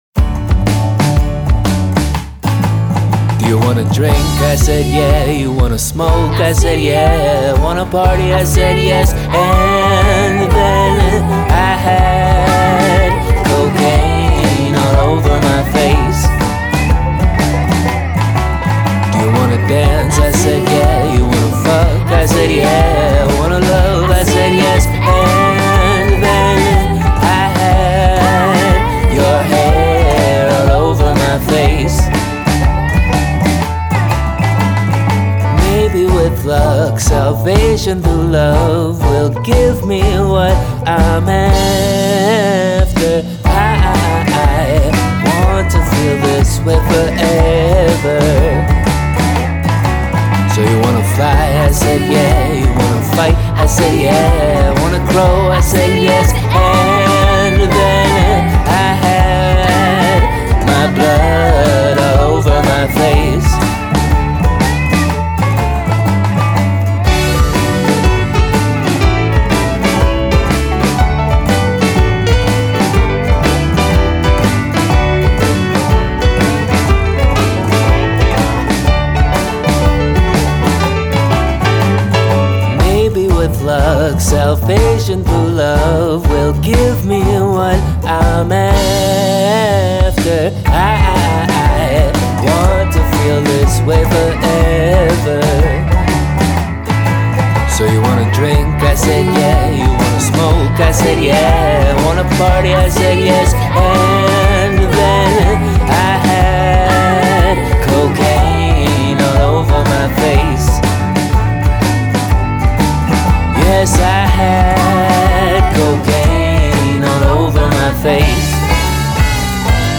I opted to do some hard panning on this to make room for everything. This is absolutely what I would consider a somewhat sloppy performance... or maybe a swampy performance... I definitely recognize that.
It includes a heavily processed upright bass, comped keyboard parts, an extremely processed dobro, a clumsy banjo part, vocals that sit outside the pocket, and layers of triggered and artificial drums.
I can’t listen on good speakers right now but nothing jumps out at me mud wise.
Reminds me of 50s music.
It gives it kind of a cool, anachronistic vibe.
Mix-wise, it may be a tad boomy in the low end, but nothing big stuck out to me.